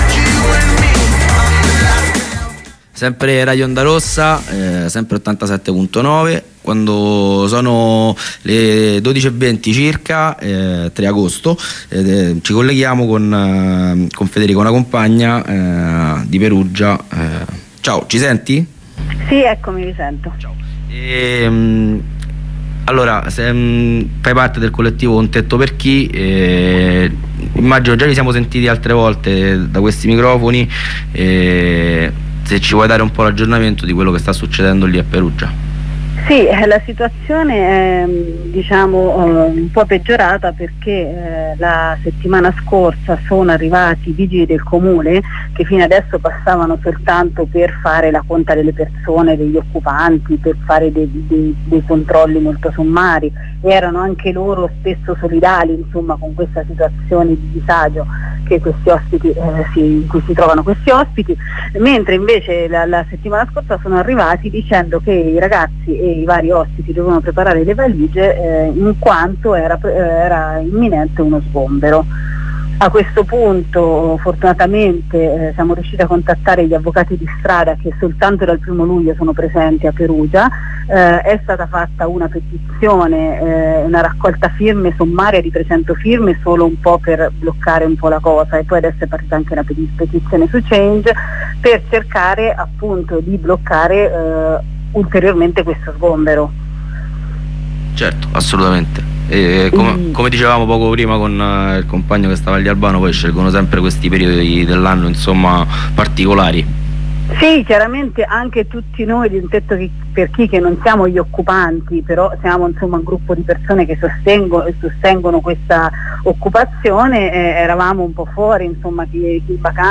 Ne parliamo con una compagna del collettivo in presidio davanti l'ex-Ostello di Rimbocchi.